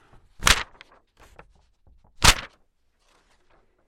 描述：我用一张复印纸打自己的脸。
Tag: 拍击 沙沙 拟音